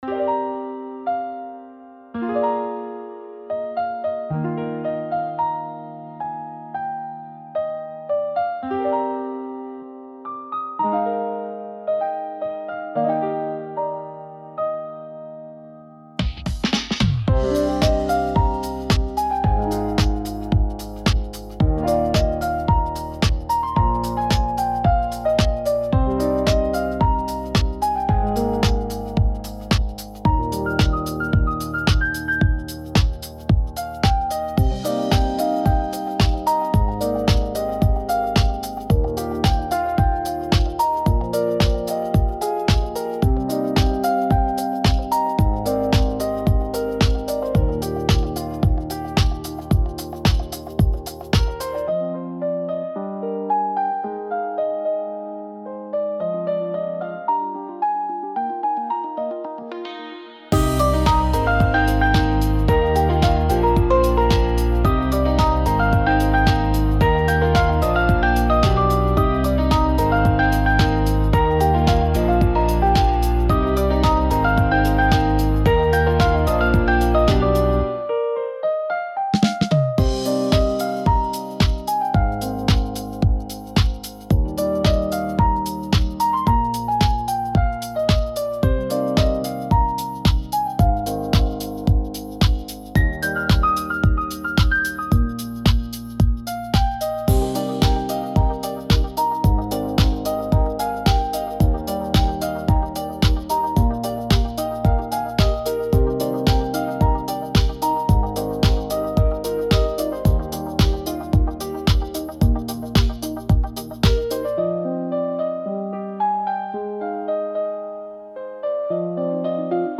シリアスっぽいので配布します。『終わり』をイメージしてみました。